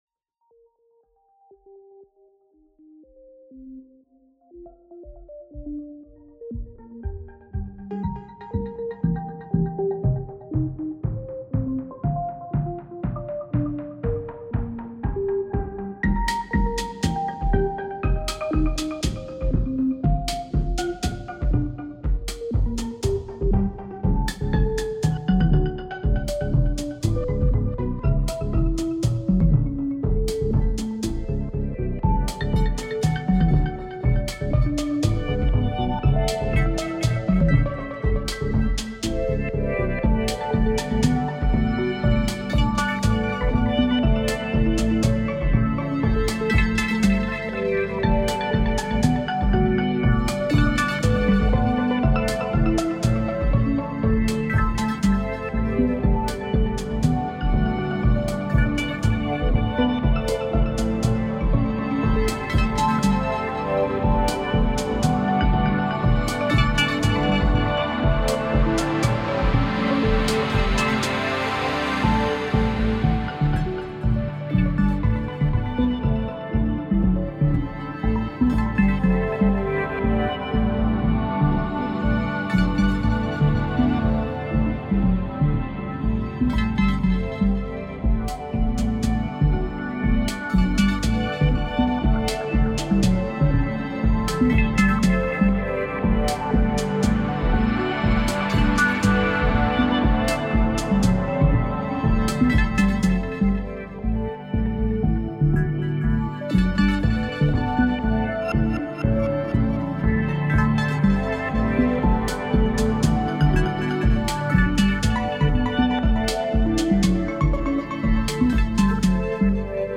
I got a French digisynth, and out came this: